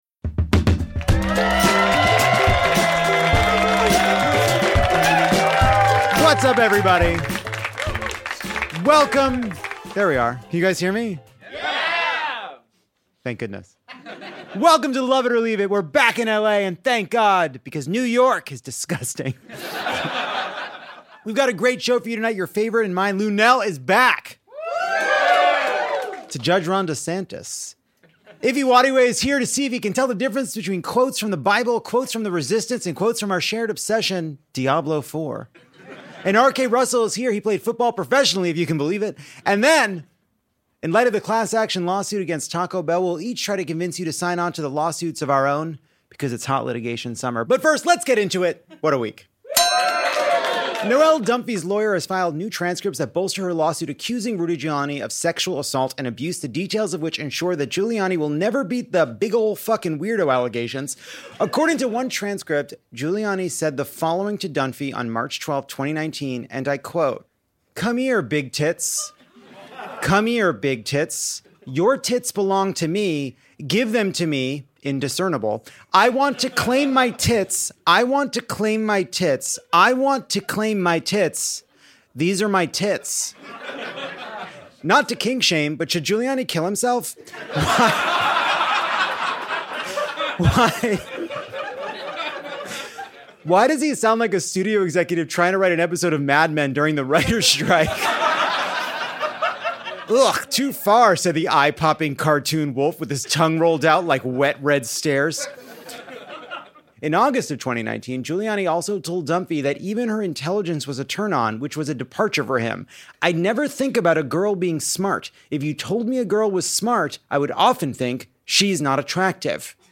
Lovett Or Leave It is hotter than hell this week at Los Angeles’ beautiful Dynasty Typewriter theater.